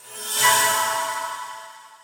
x_enchanting_enchant.ogg